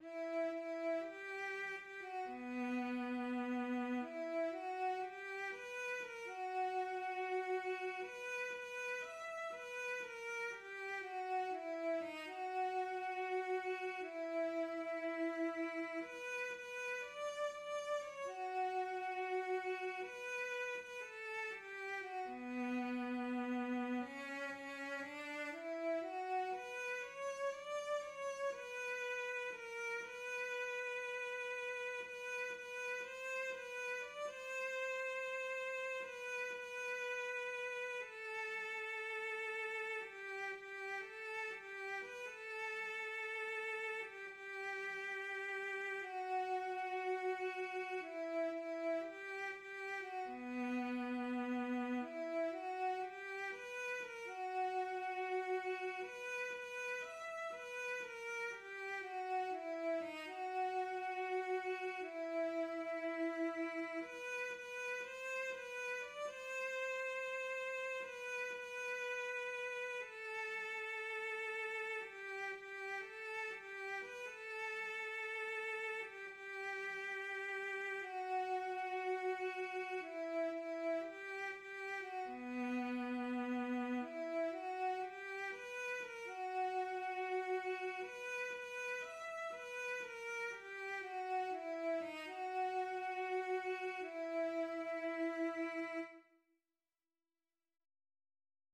Műfaj magyar könnyűzenei dal